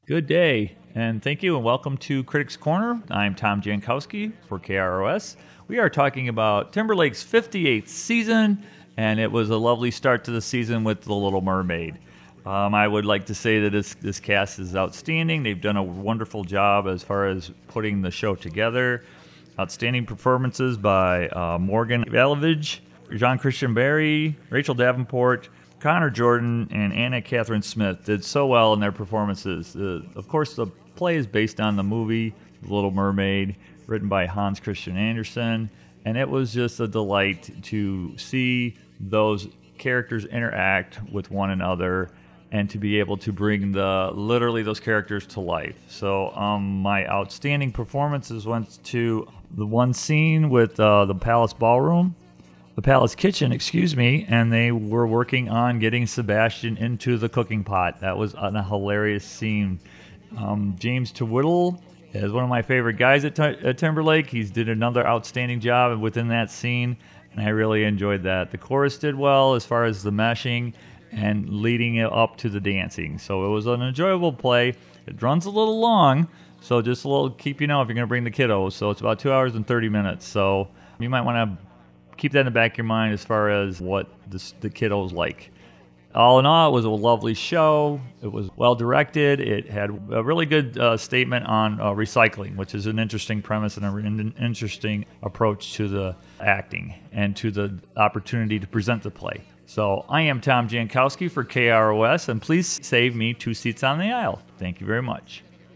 Little-Mermaid-Review.wav